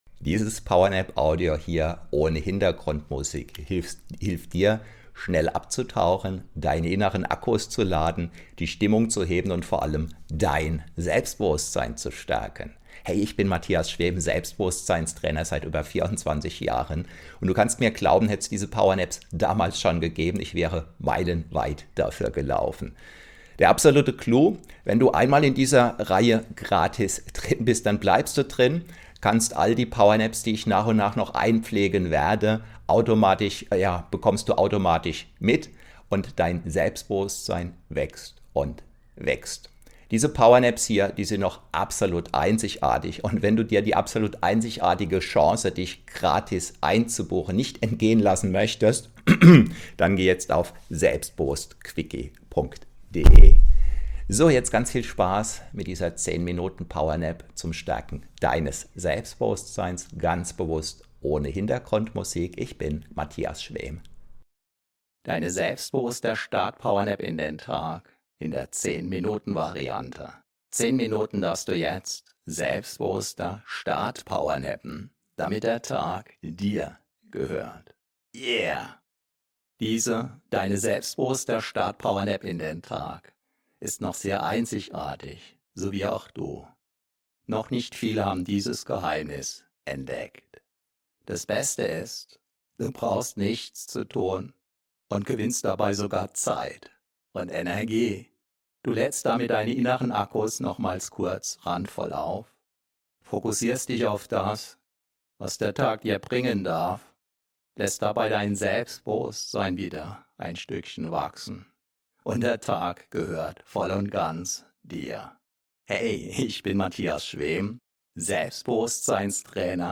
Mit dieser PowerNap setzt du die richtigen Impulse und legst den Grundstein für einen erfolgreichen, selbstbewussten Tag.